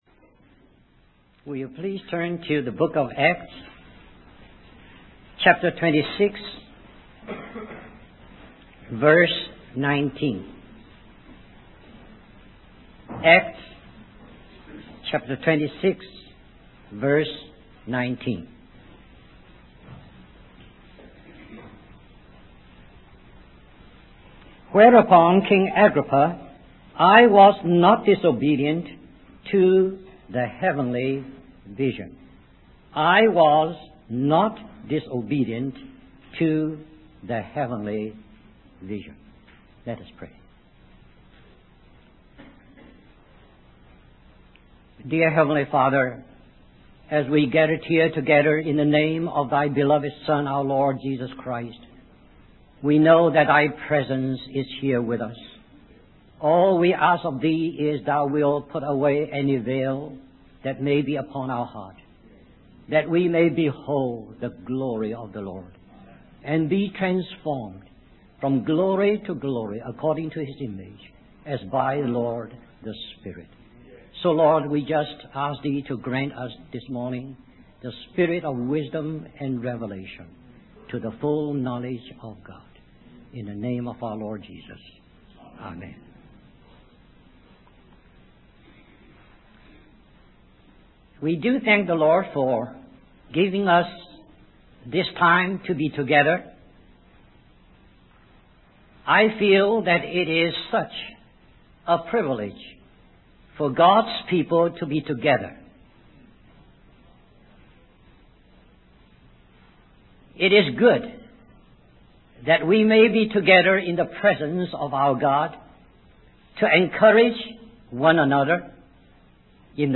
In this sermon, the speaker emphasizes the importance of having a heavenly vision in the spiritual realm. He explains that without a vision, people become directionless and lack purpose. The speaker references Proverbs 29:18, which states that without vision, people perish.